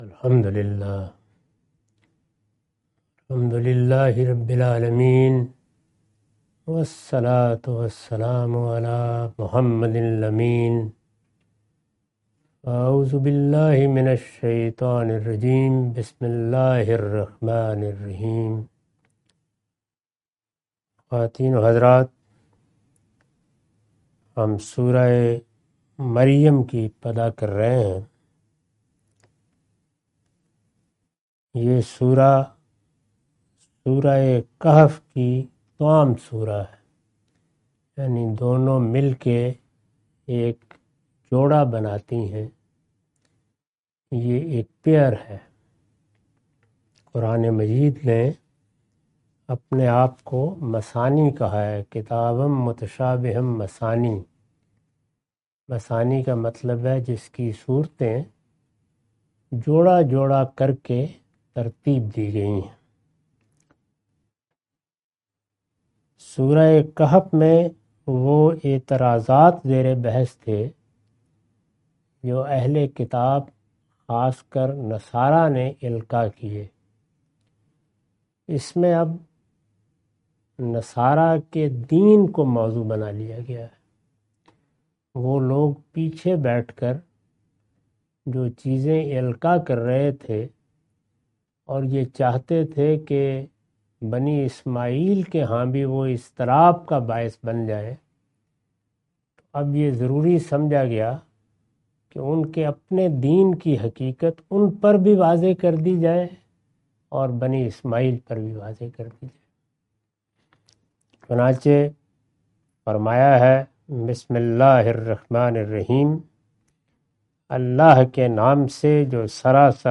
Surah Maryam A lecture of Tafseer-ul-Quran – Al-Bayan by Javed Ahmad Ghamidi. Commentary and explanation of verses 01-05.